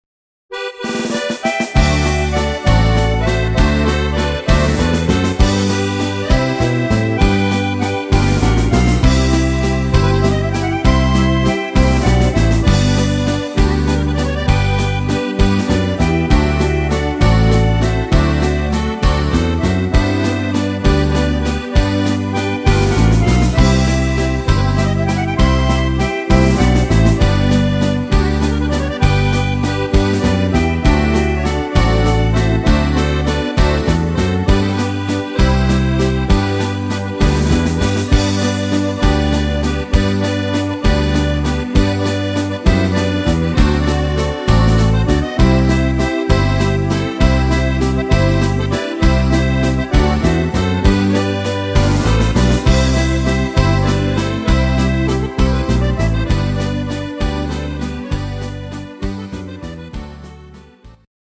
Walzer aus dem Elsass